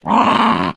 Minecraft Version Minecraft Version latest Latest Release | Latest Snapshot latest / assets / minecraft / sounds / mob / wolf / sad / growl2.ogg Compare With Compare With Latest Release | Latest Snapshot
growl2.ogg